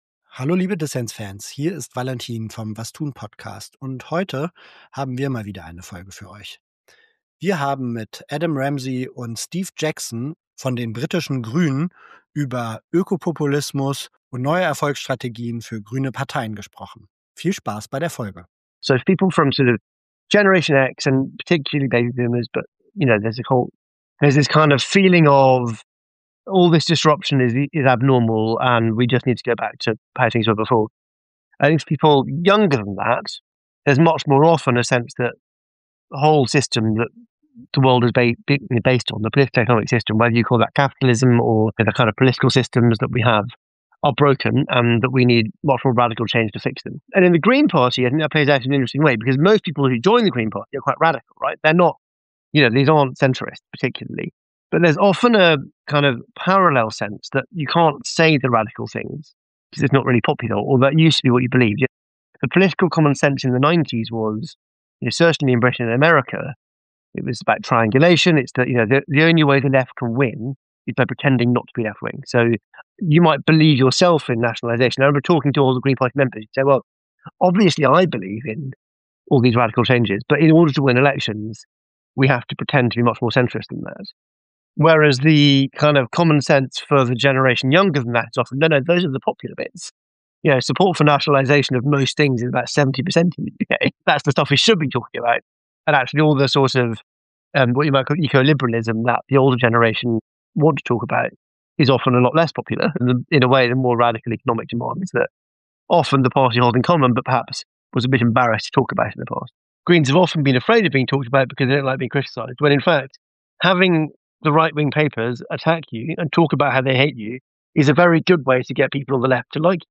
Das Erfolgsrezept: Die Green Party UK setzt auf Ökopopulismus statt auf grünen Liberalismus, wie ihre deutsche Schwesterpartei. Im Interview